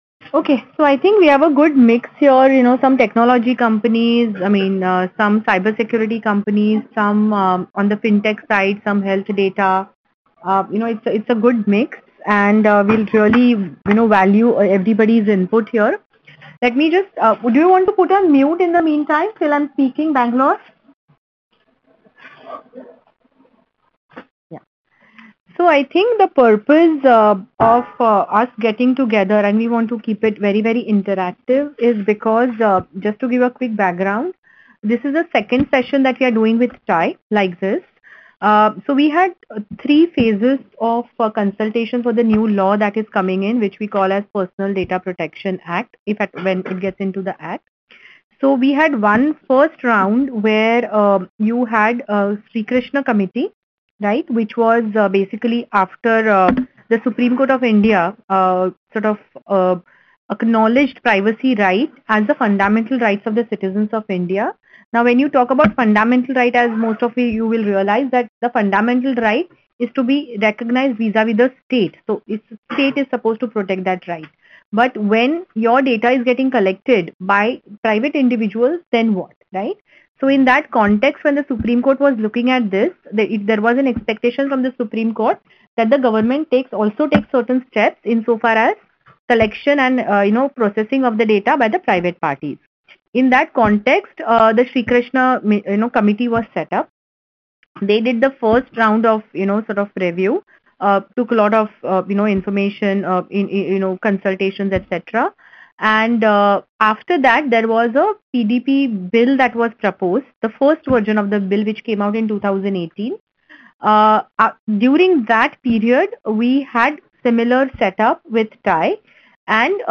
Seminar: Possible Last Window for the Start-Up Community’s Say on Proposed Privacy Law